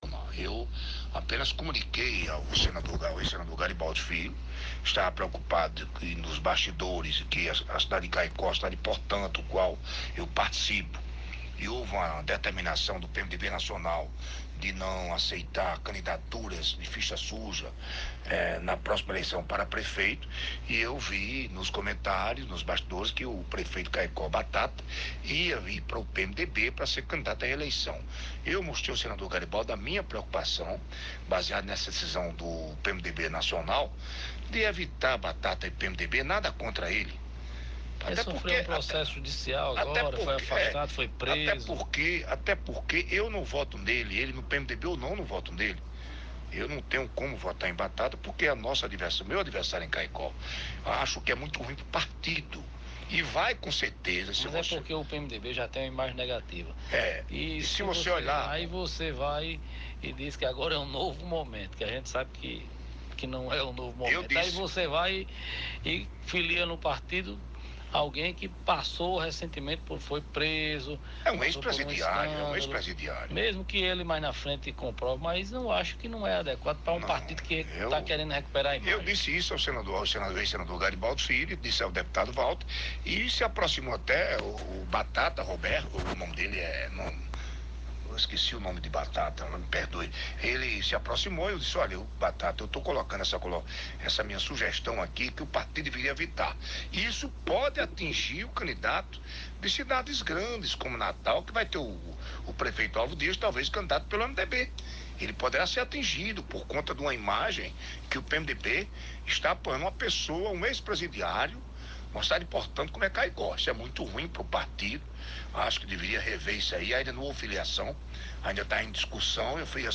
O deputado estadual Nelter Queiroz afirmou em entrevista ter alertado o ex-senador Garibaldi Alves Filho a preocupação com a filiação ao MDB do prefeito de Caicó, Robson Araújo (Batata). Reconhecendo que o prefeito de Caicó é seu adversário político e que mesmo estando no mesmo partido, Nelter diz não ter como apoiar um projeto de reeleição.